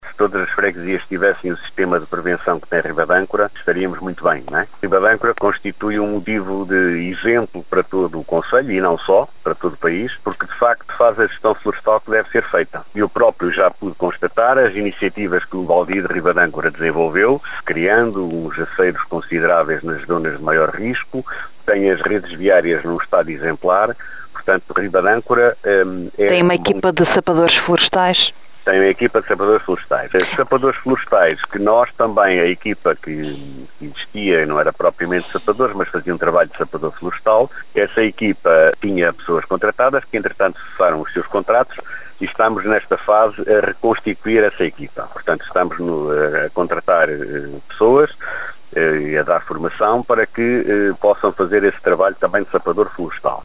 Guilherme Lagido revelou à Rádio Caminha a estratégia que vai ser adoptada pelo município para evitar ou diminuir o número de incêndios florestais.